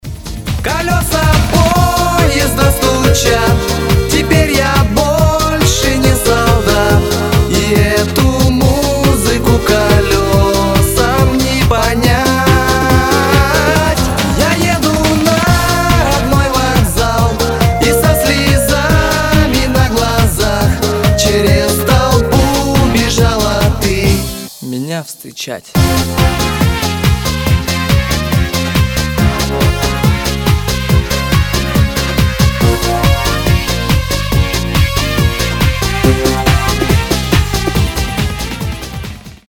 • Качество: 320, Stereo
армейские
военные
солдатские